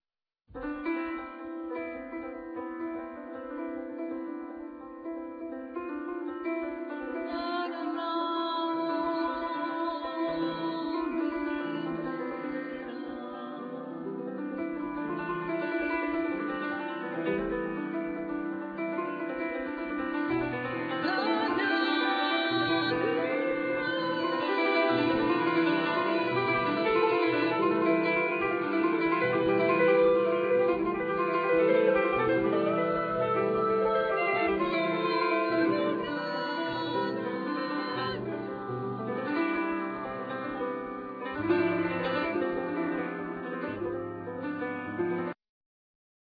Piano
Keyboards
Soprano Saxophne
Vocal
Bass
Drums
Percussion